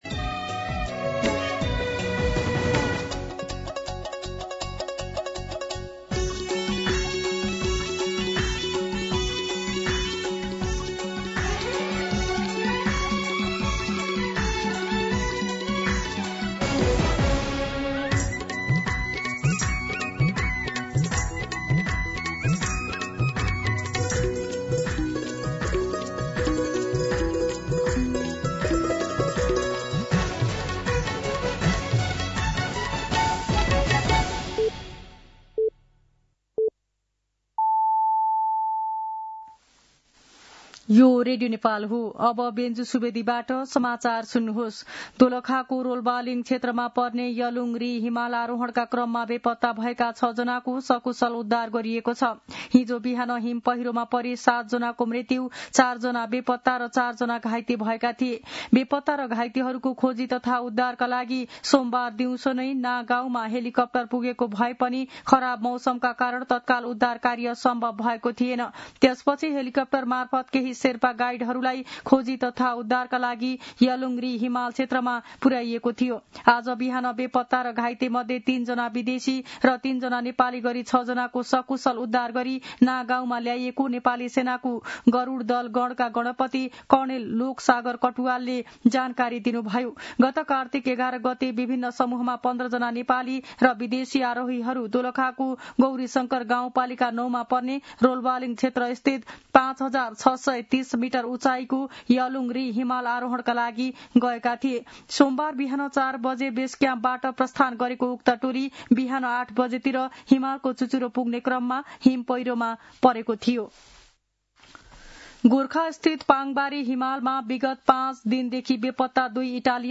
दिउँसो १ बजेको नेपाली समाचार : १८ कार्तिक , २०८२